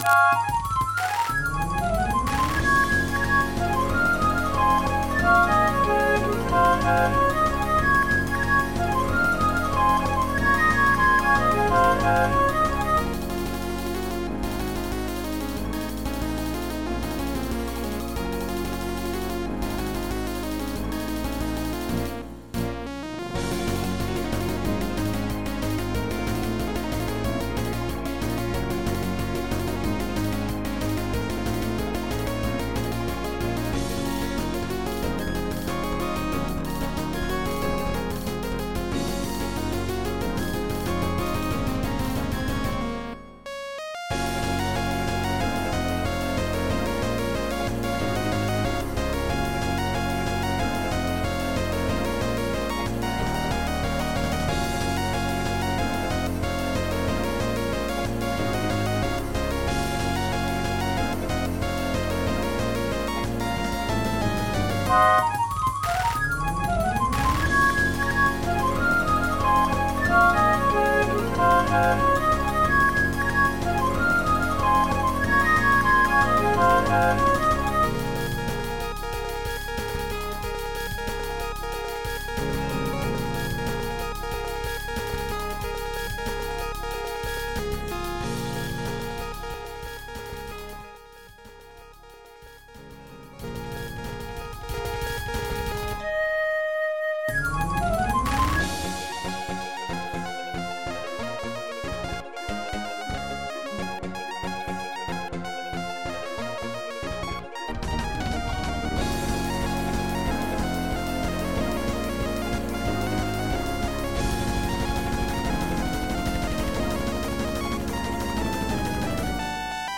MIDI 68.26 KB MP3 (Converted) 2.4 MB MIDI-XML Sheet Music
J-pop/happy hardcore song